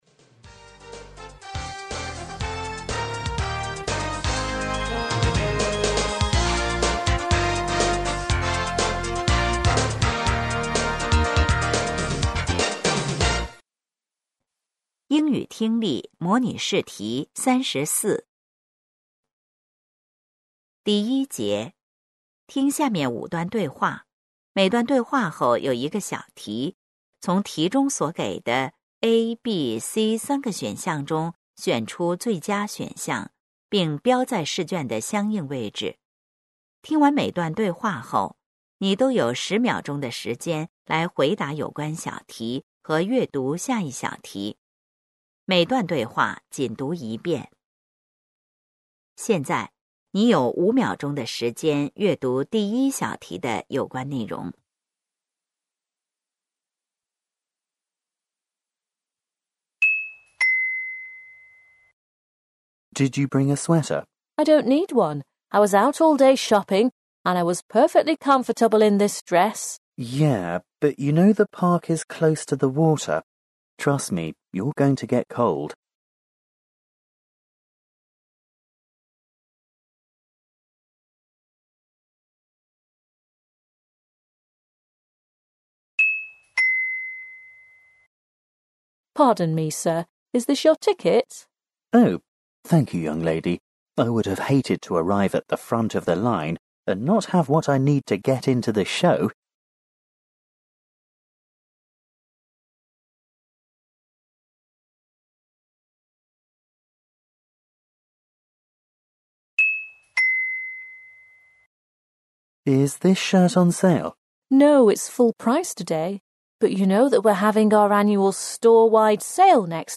成都树德中学2025届高三上学期开学考试英语听力.mp3